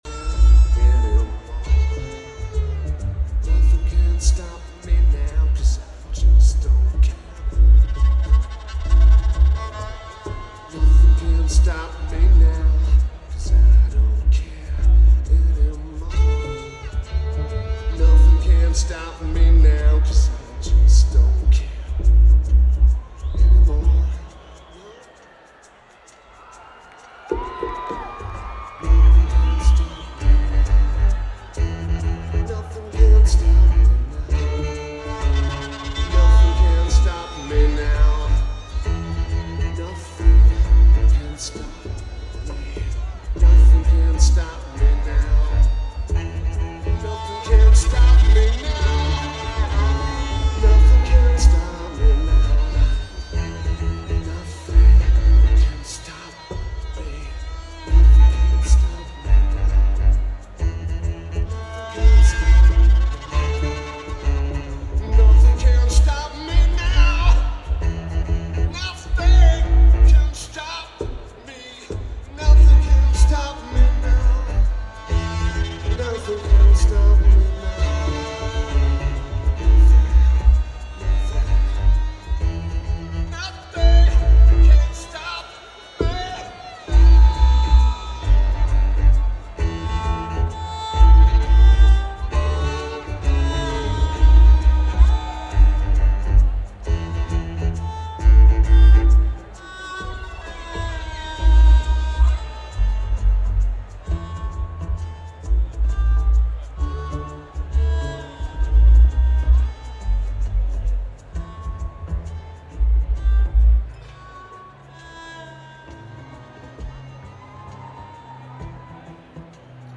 Shoreline Amphitheater
Vocals/Guitar/Keyboards
Notes: Great recording, the same taper from the next night.